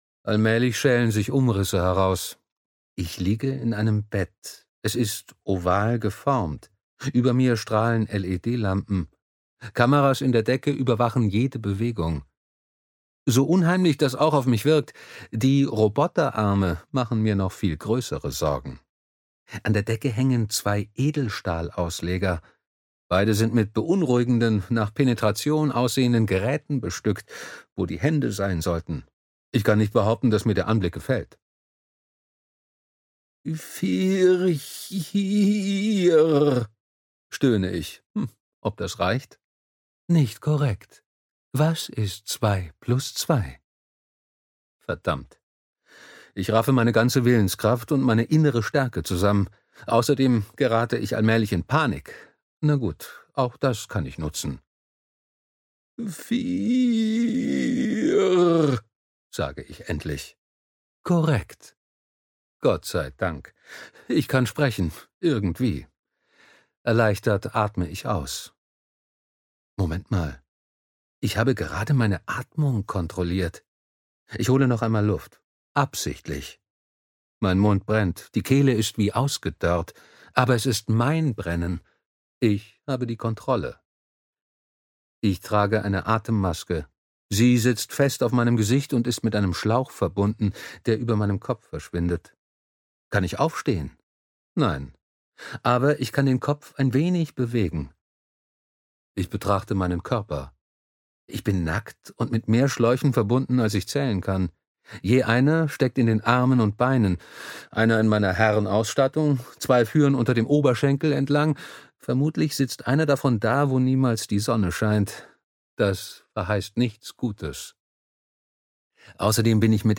Der Astronaut (DE) audiokniha
Ukázka z knihy